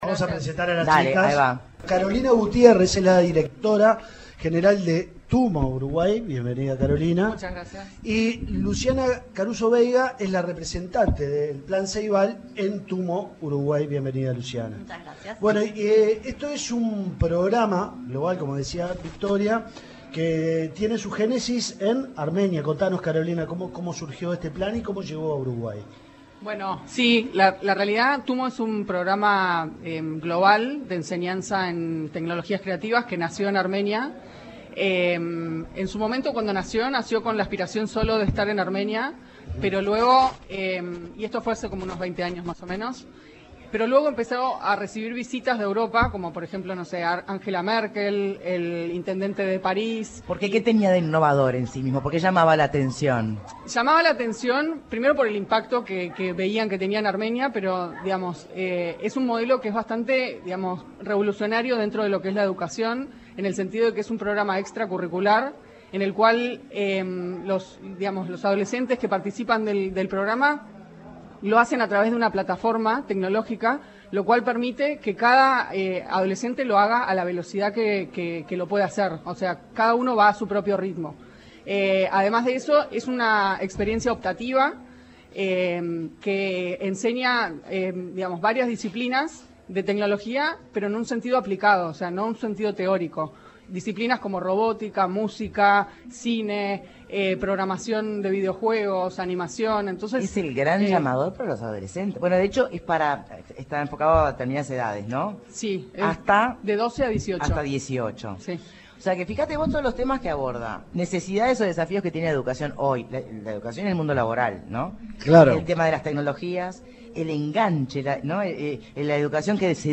En entrevista